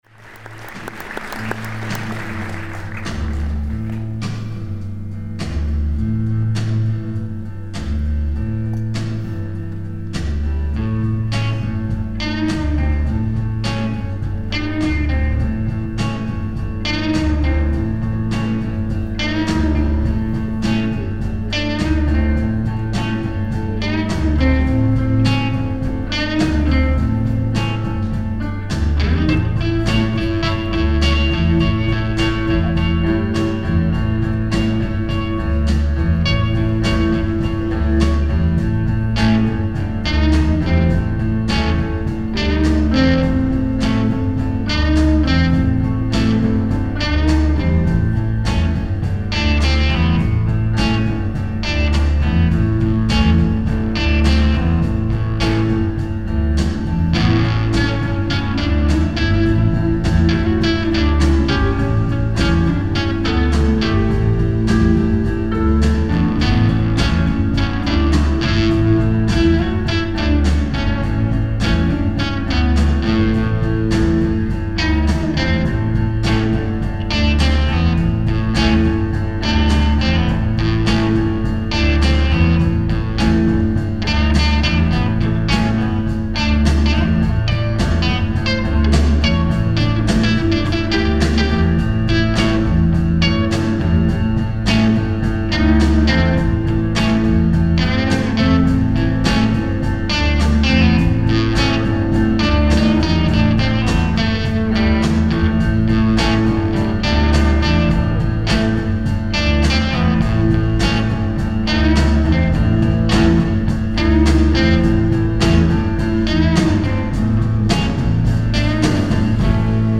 Velvet Underground inspired stylings